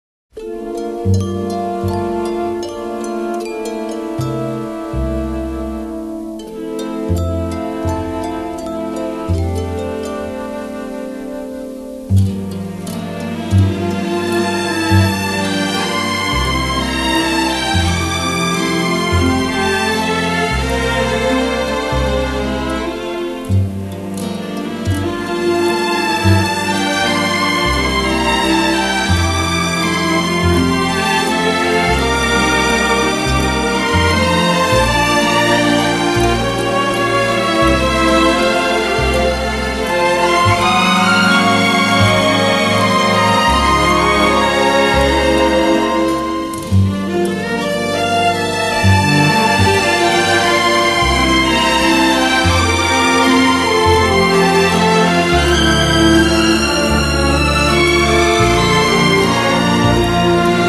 Adding rich contrast is gorgeous, shimmering love theme.